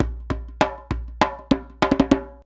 100DJEMB34.wav